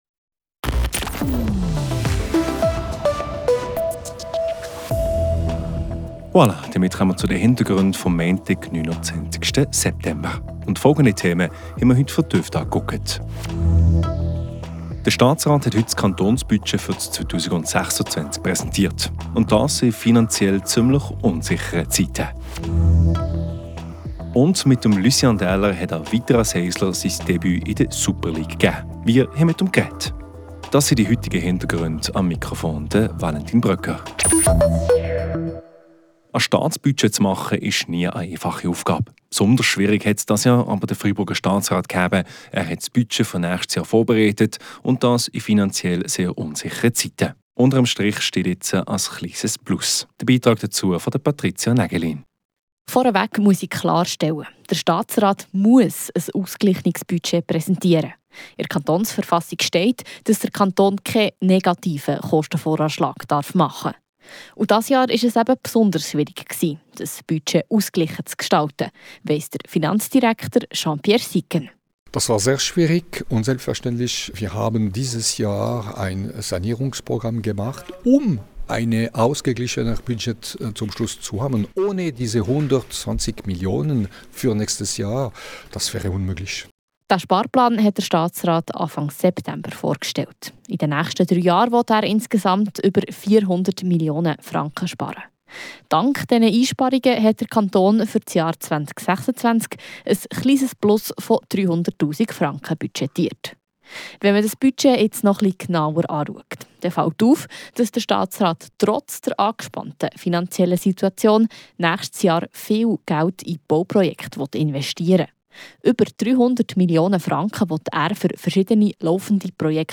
Wir haben mit ihm nach dem Spiel gesprochen.